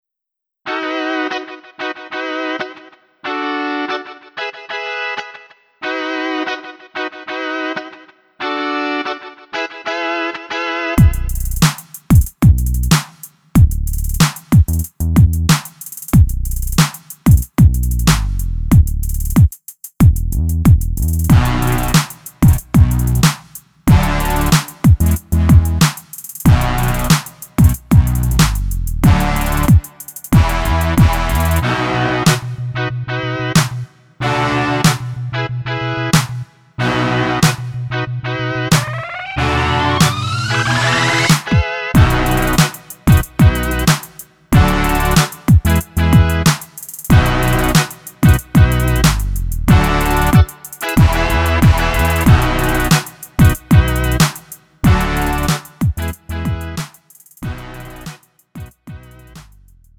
음정 원키 2:49
장르 가요 구분 Lite MR